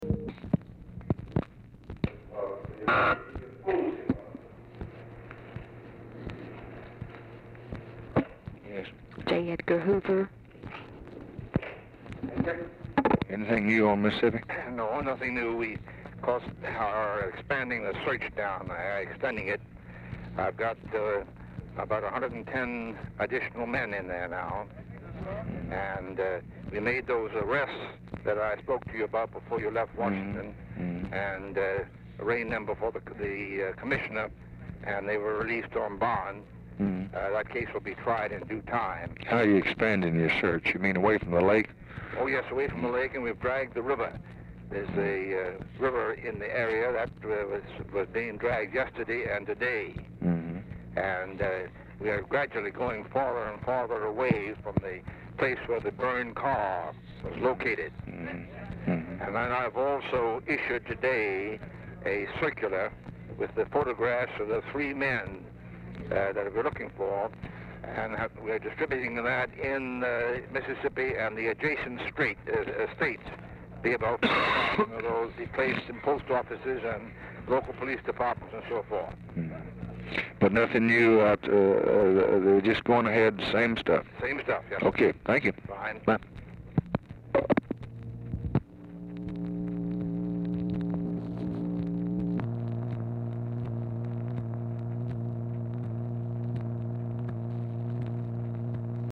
Telephone conversation # 3941, sound recording, LBJ and J. EDGAR HOOVER, 6/29/1964, 1:09PM | Discover LBJ
Format Dictation belt
Location Of Speaker 1 Oval Office or unknown location
Specific Item Type Telephone conversation